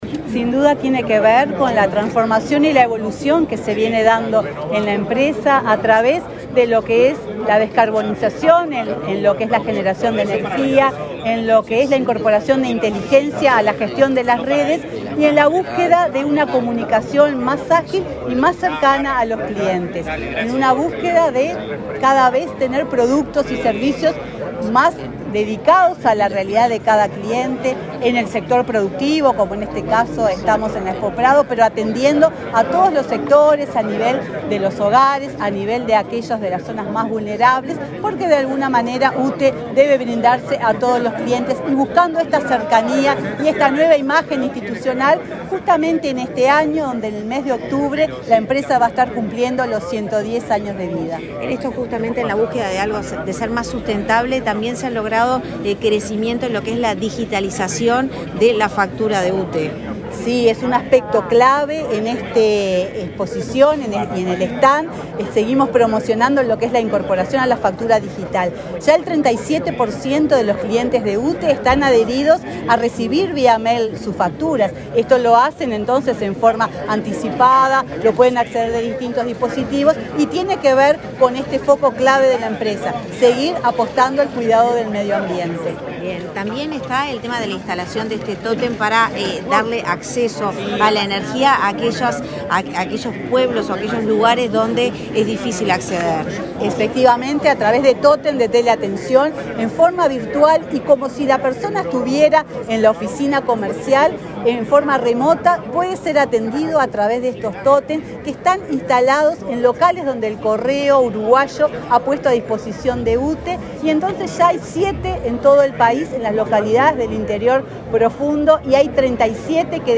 Declaraciones de la presidenta de UTE
Luego Emaldi dialogó con la prensa.